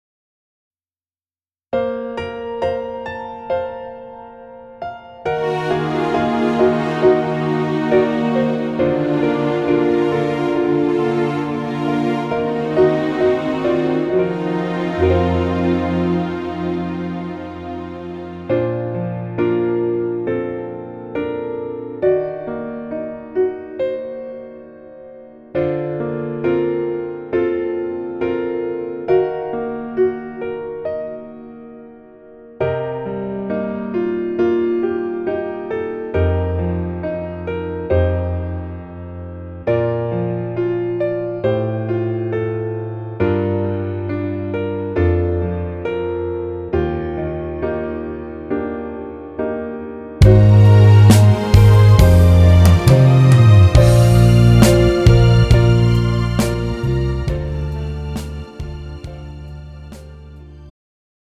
음정 여자키
장르 축가 구분 Pro MR
가사   (1절 앞소절 -중간삭제- 2절 후렴연결 편집)